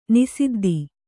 ♪ nisiddi